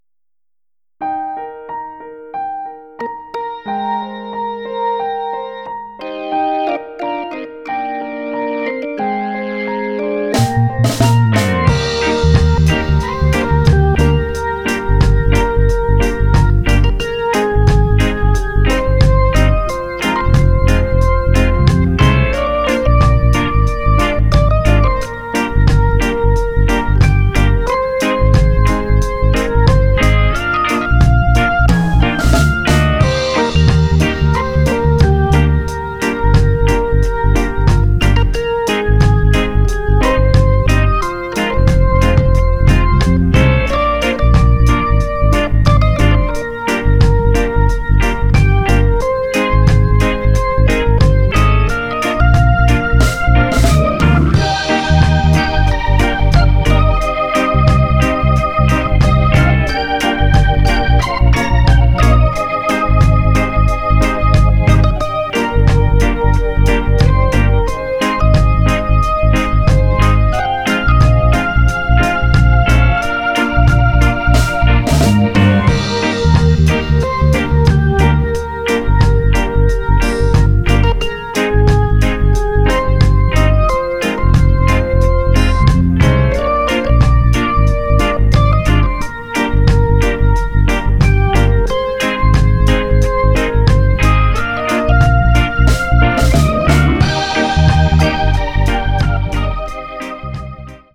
魅惑のハモンドオルガンの調べを南国ラガエのリズムに乗せて贈る懐かしのあのメロディ。
ジャンル(スタイル) JAPANESE REGGAE